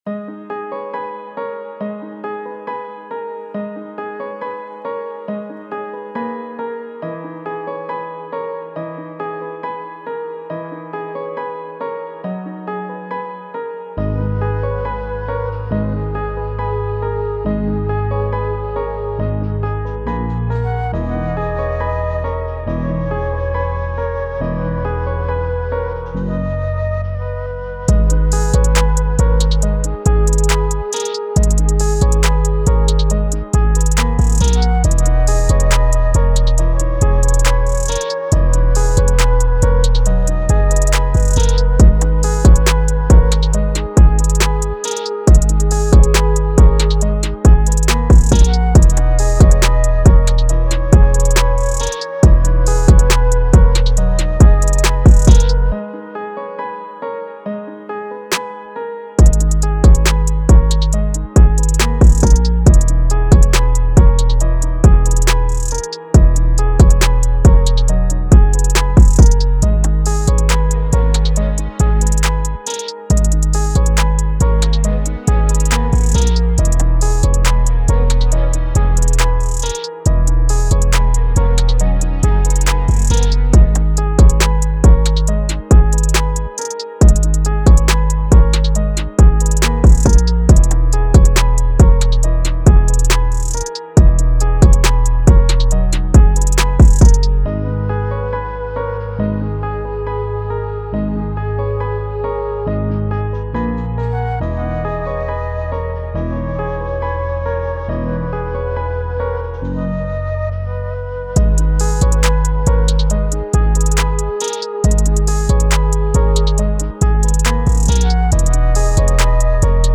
Rap
Ab minor